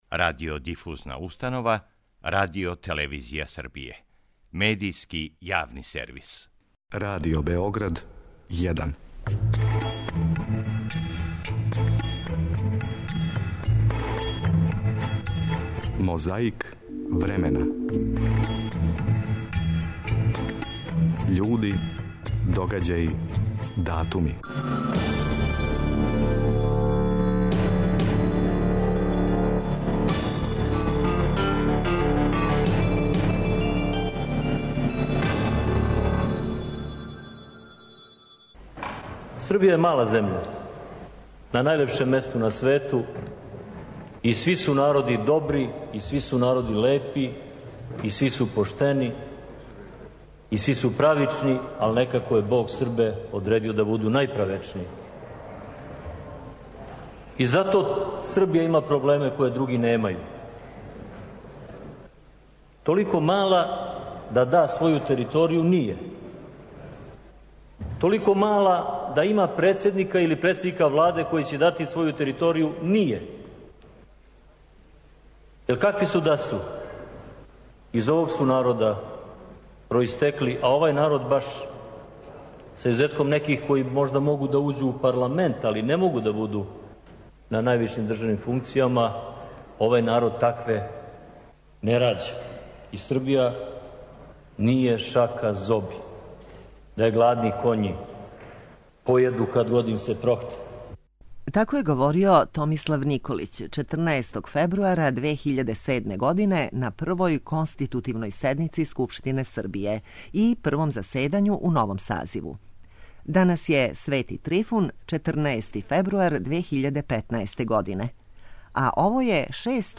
У Београду је 14. фебруара 1961. године одржан протестни митинг поводом убиства Патриса Лумумбе, првог председника владе независног Конга.
Говорили су тада и Војислав Коштуница и Томислав Николић.
Неки од присутних су глумили, неки певали, неки говорили.